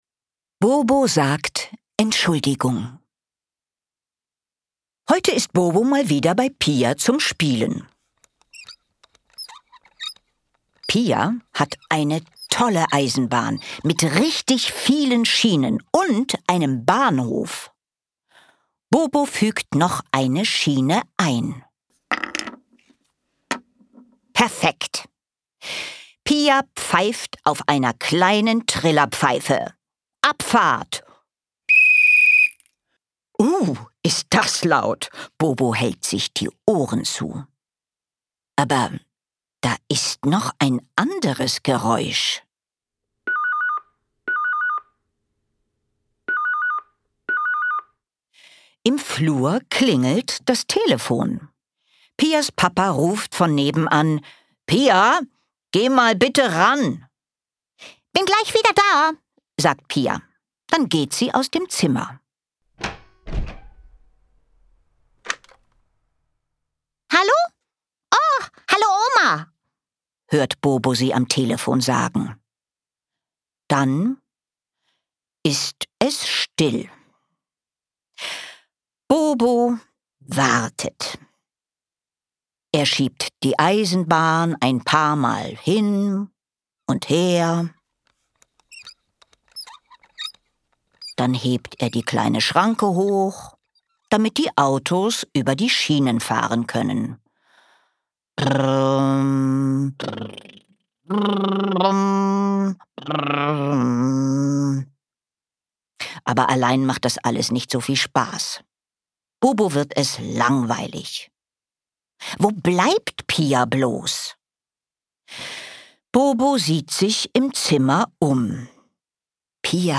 Geschichten für Kleine mit KlangErlebnissen und Liedern